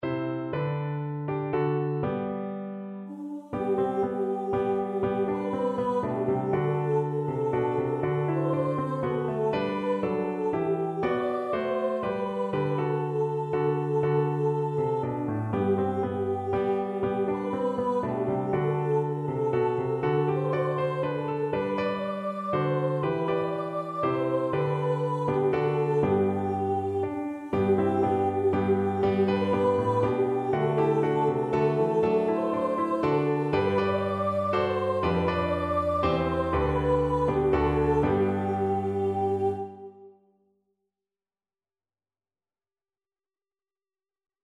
Voice 1Voice 2
3/4 (View more 3/4 Music)
Lively ( = c.120)
Vocal Duet  (View more Easy Vocal Duet Music)